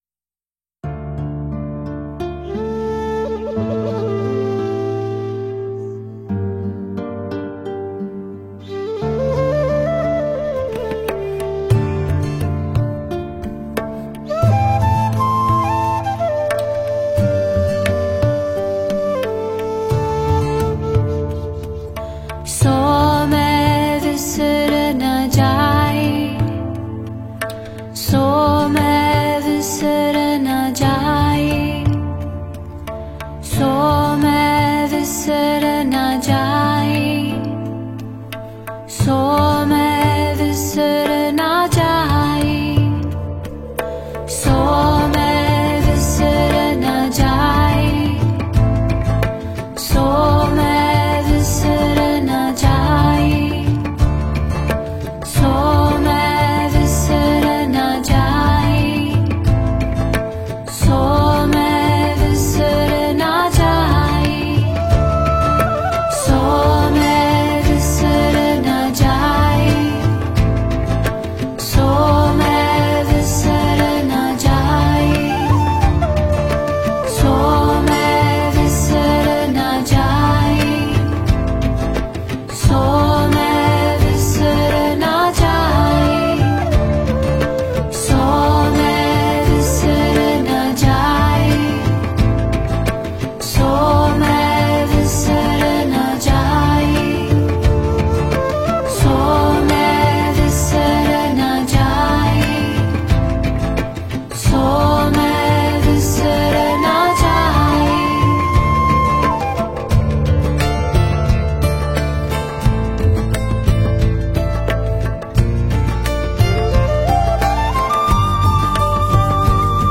佛音 诵经 佛教音乐 返回列表 上一篇： Servant of Peace 下一篇： 大悲咒(男声版